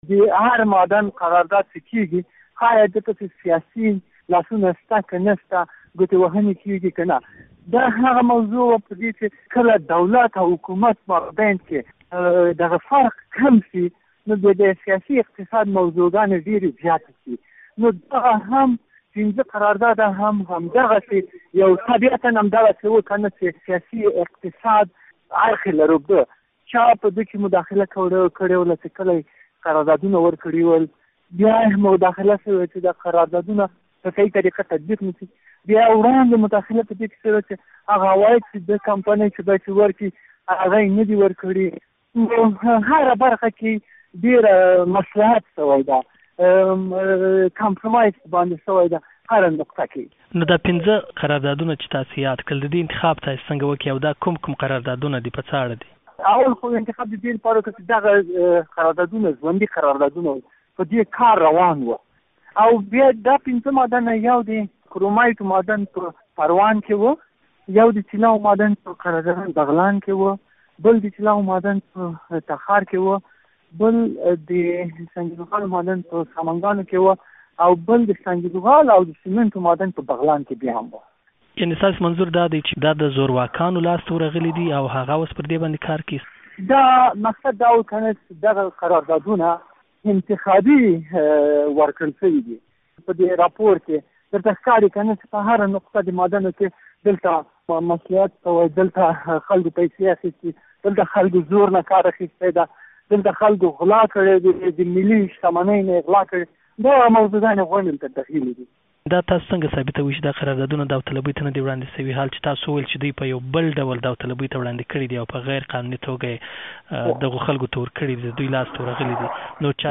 مرکې، شننې، تبصرې